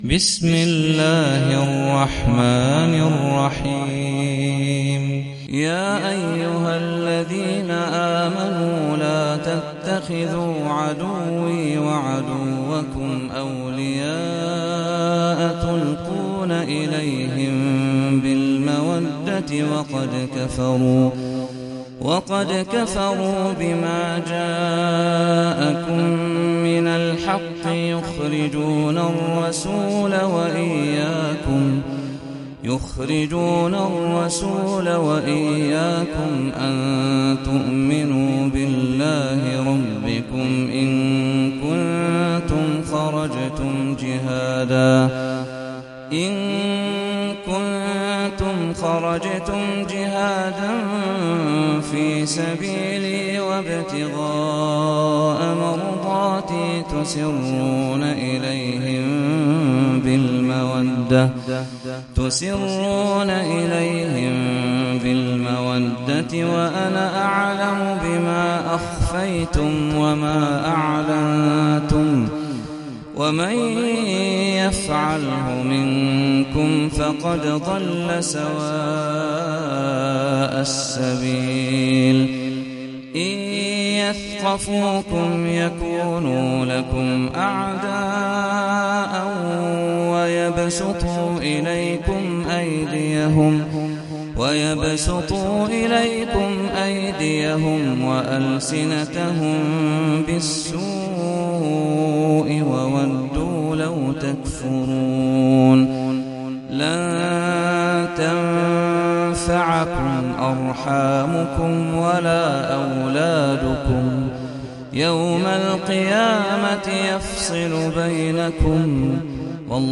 سورة الممتحنة - صلاة التراويح 1446 هـ (برواية حفص عن عاصم)
جودة عالية